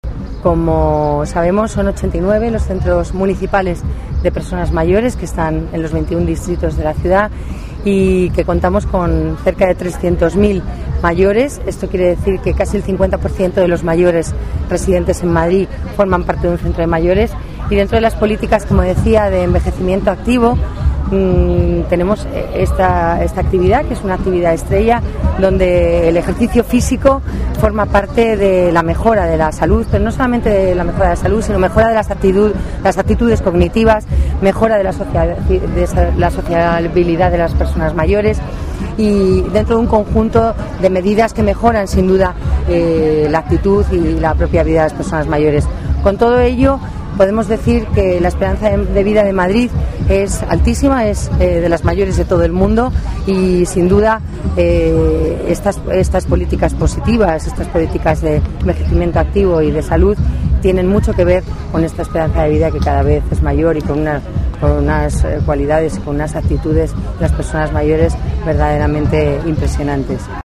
Nueva ventana:Declaraciones de la delegada de Familia, Servicios Sociales y Participación Ciudadana, Dolores Navarro